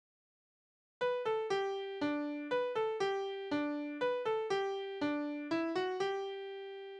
Tanzverse:
Tonart: G-Dur
Taktart: 3/4
Tonumfang: große Sexte
Besetzung: vokal
Anmerkung: Tanz